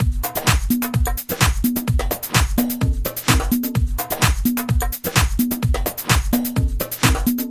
描述：有人会说这是民族鼓，但我用的是House drums和"African drumsquot。
Tag: 128 bpm House Loops Drum Loops 937.54 KB wav Key : Unknown